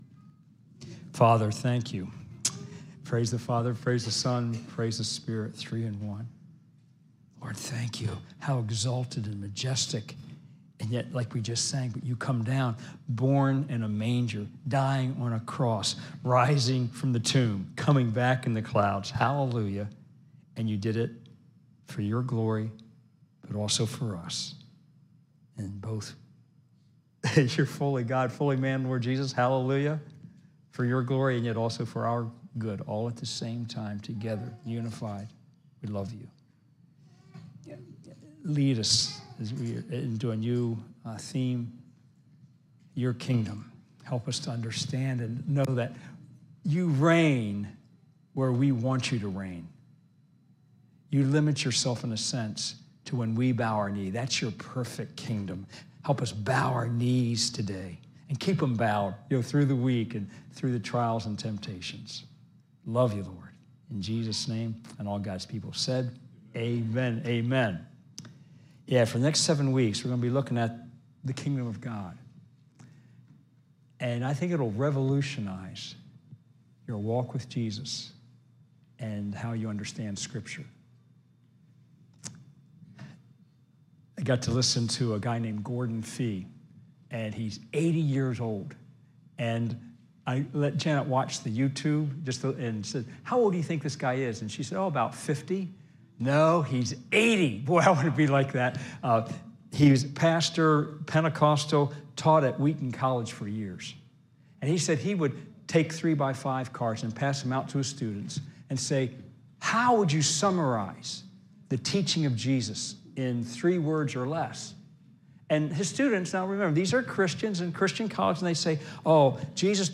live stream worship service
4-24-22-Sermon-Audio.mp3